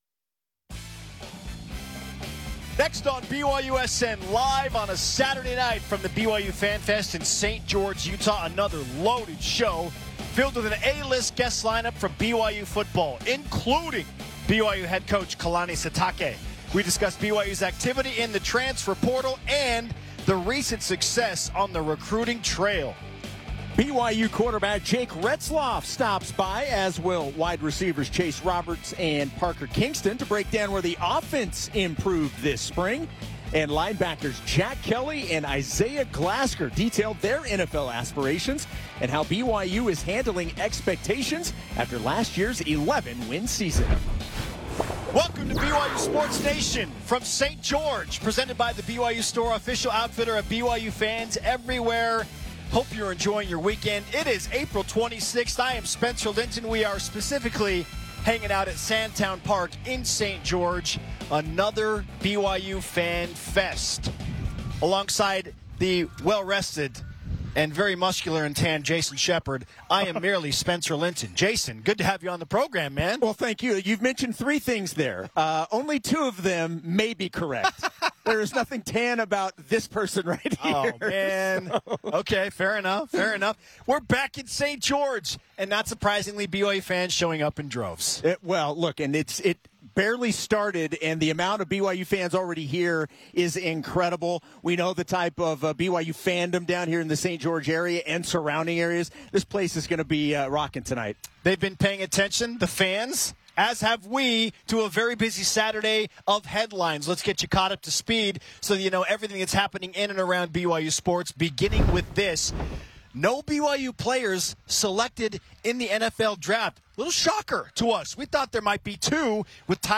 Live on (12-26-25).